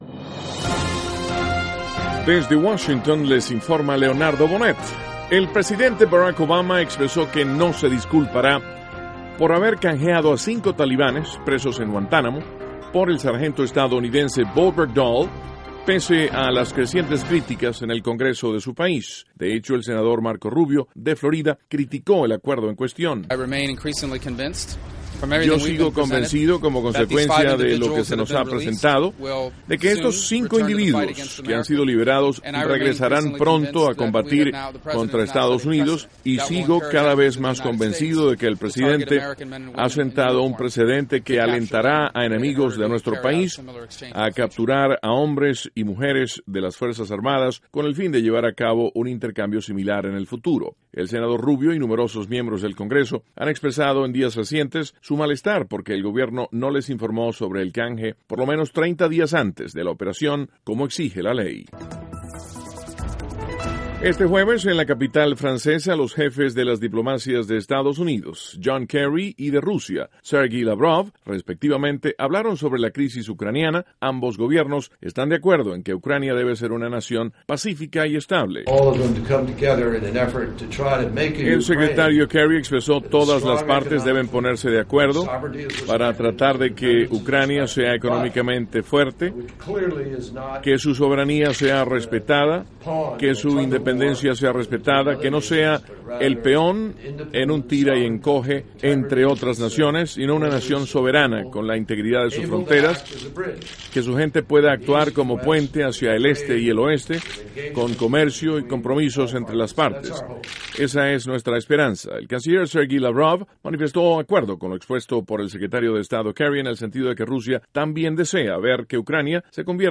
NOTICIAS - JUEVES, 5 DE JUNIO DE 2014
Duración: 3:20 Contenido: 1.- El senador Marco Rubio, de Florida, criticó al presidente Obama por el intercambio de 5 talibanes por un sargento estadounidense. (Sonido Rubio) 2.- Los cancilleres de Estados Unidos y de Rusia coinciden en París respecto a la situación de Ucrania. (Sonido Kerry) 3.- Departamento de Estado considera que juicio de Leopoldo López no contribuye al diálogo en Venezuela.